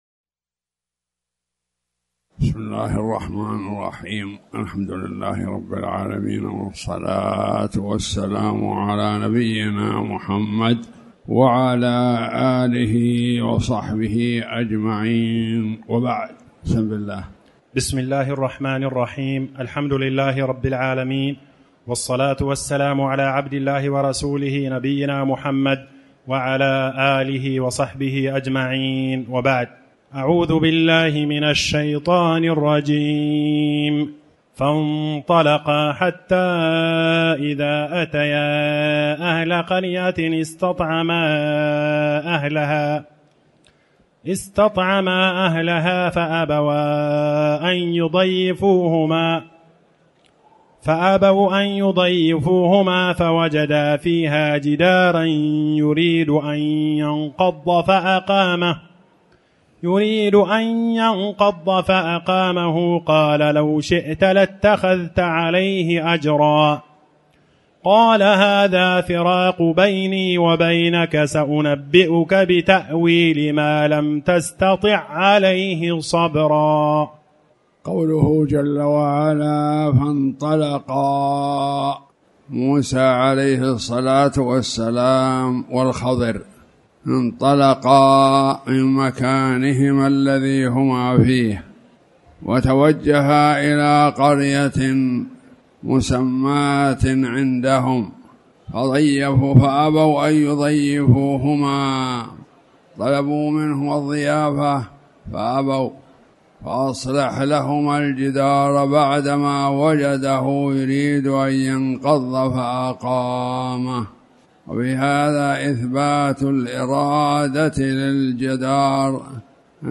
تاريخ النشر ٥ صفر ١٤٣٩ هـ المكان: المسجد الحرام الشيخ